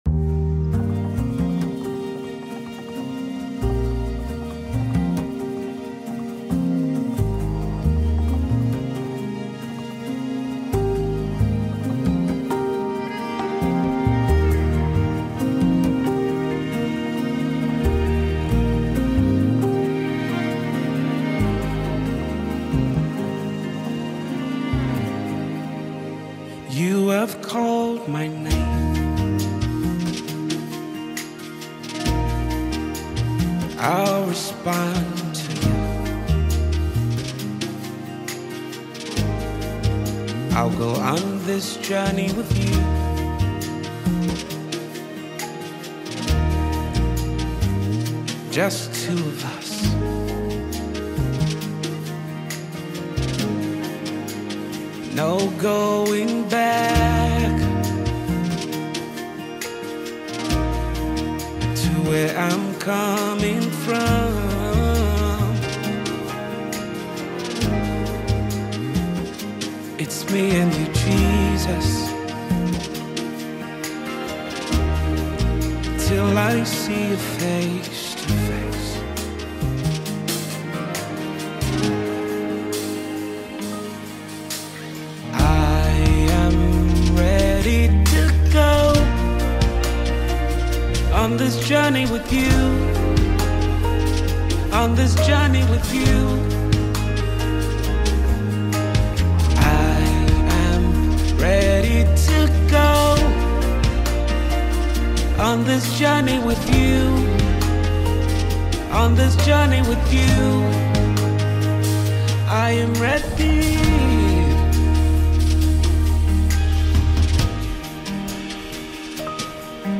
Prominent Nigerian Singer